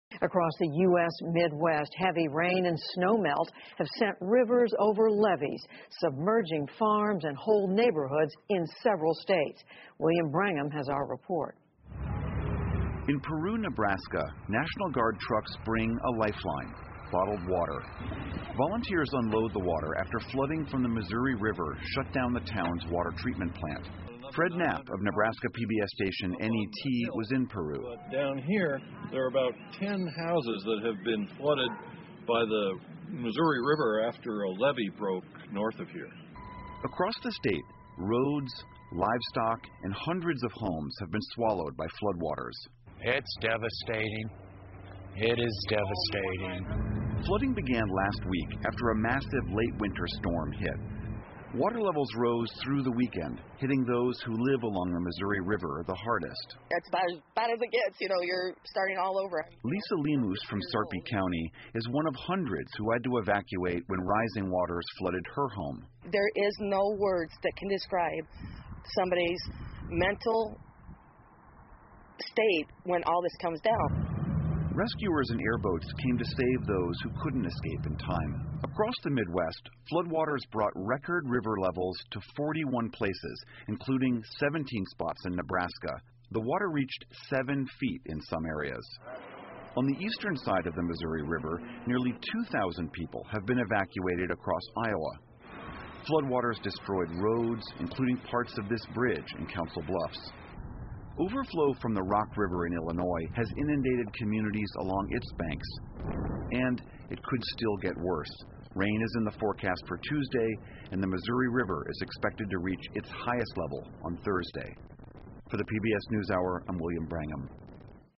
PBS高端访谈:美国中西部迎来特大洪水 听力文件下载—在线英语听力室